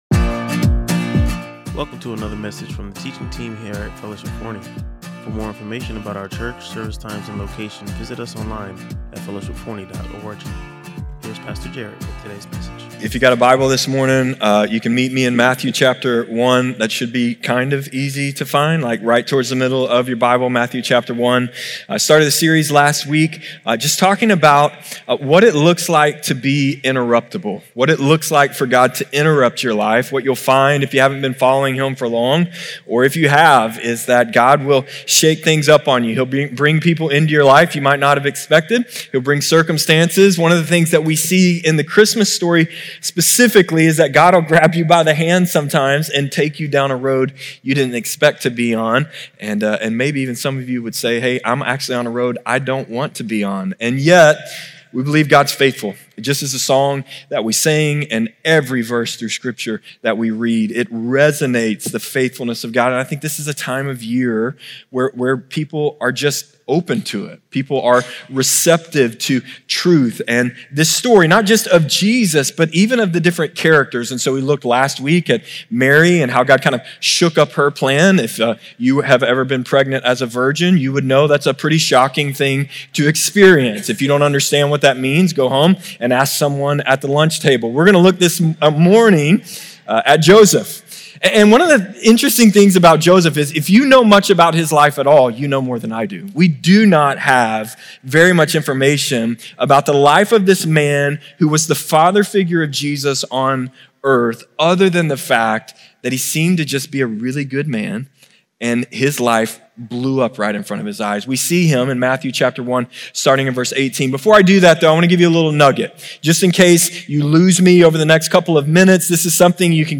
Listen to or watch the full sermon and discover how Joseph’s story of faith and obedience can inspire your own walk with God.